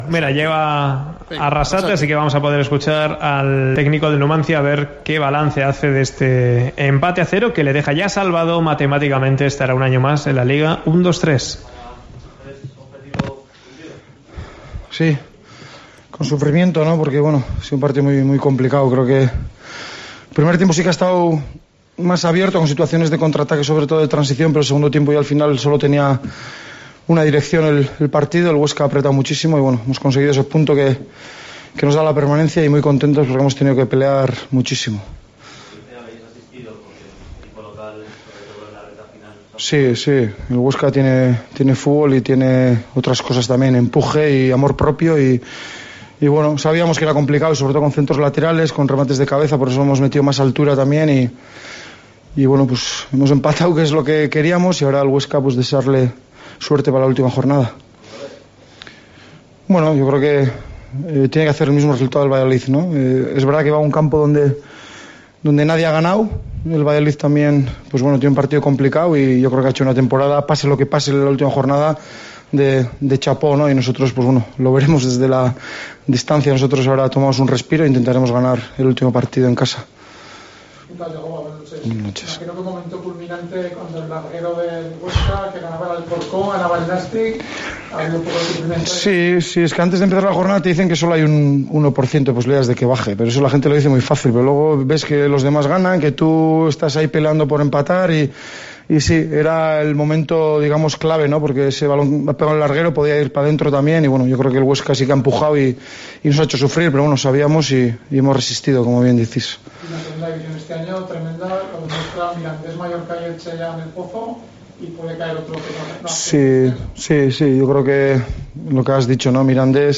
Escuchamos al entrenador del Numancia tras empatar (0-0) en Huesca.